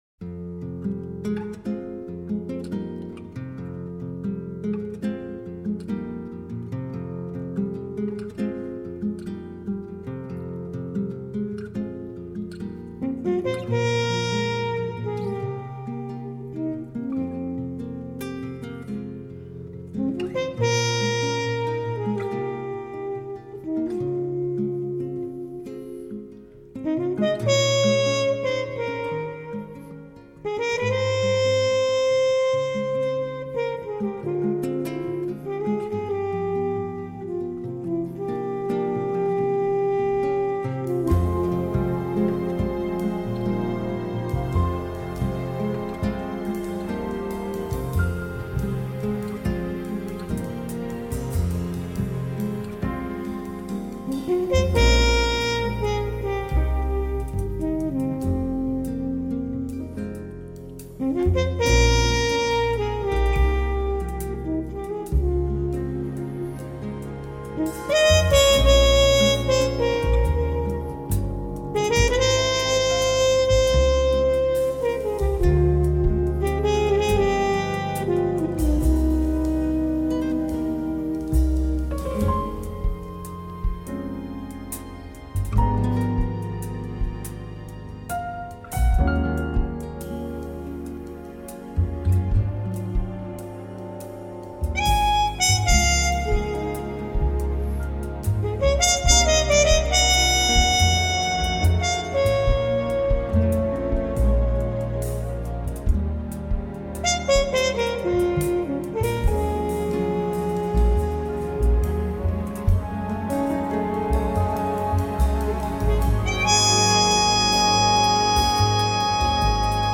爵士小号手
小号演奏